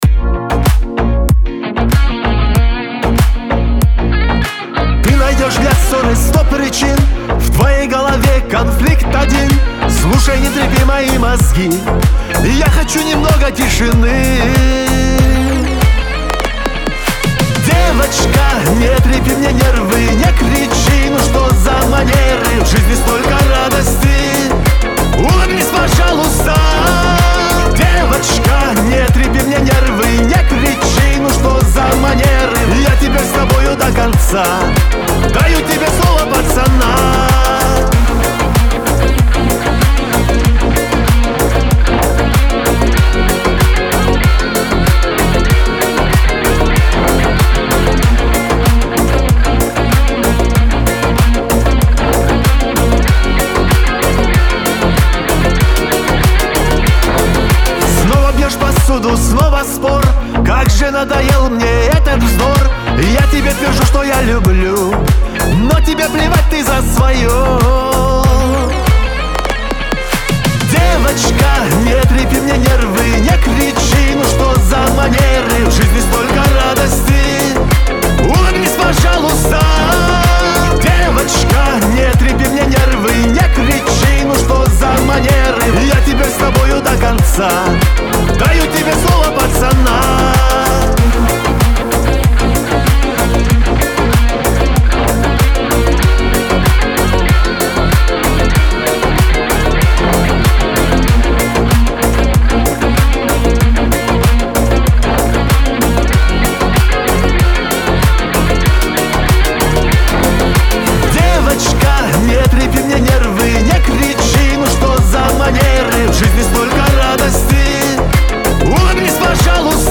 Кавказ – поп
Лирика